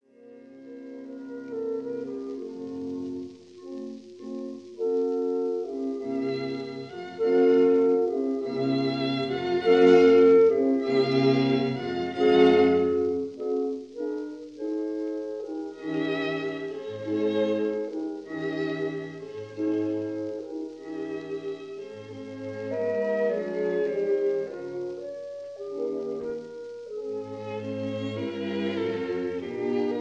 clarinet
bassoon
horn
violins
viola
cello
double-bass
Recorded in Société suisse de radiodiffusion
studio, Geneva in July 1948